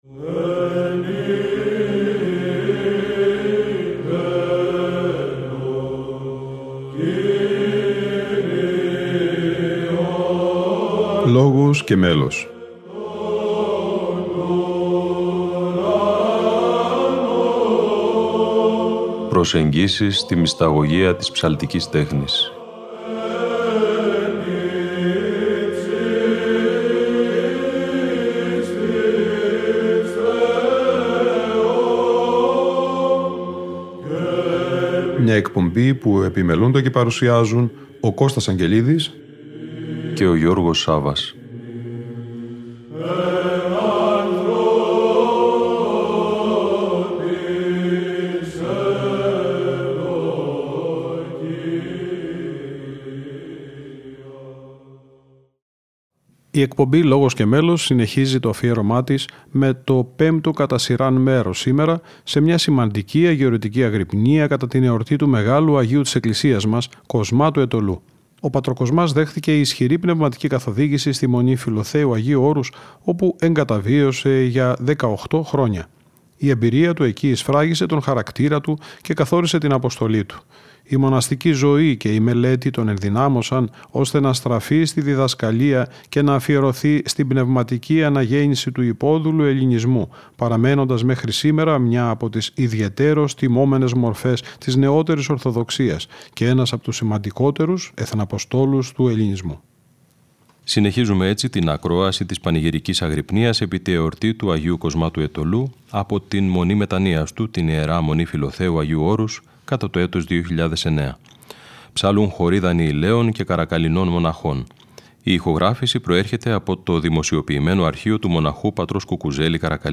Αγρυπνία Αγ. Κοσμά Αιτωλού - Ι. Μ. Φιλοθέου 2009 (Ε΄)